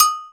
089 - Agogo.wav